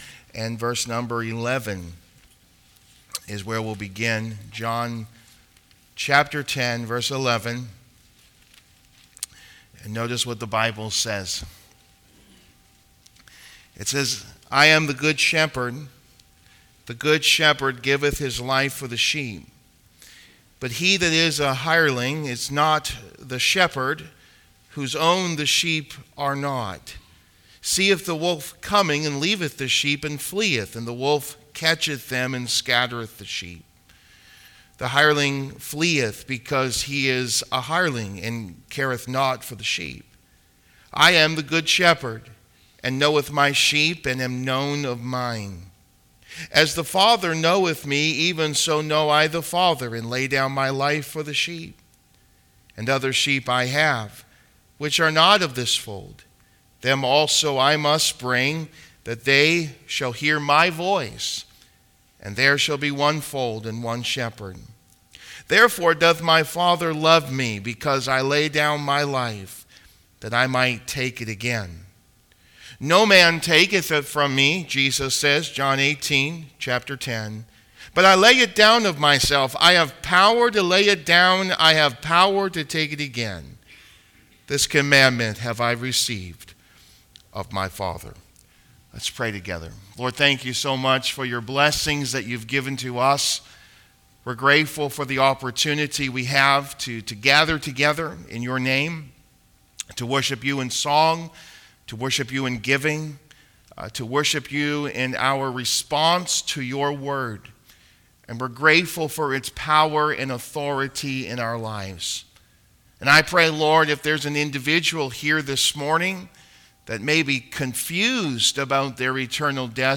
Kitchener Baptist Church Podcast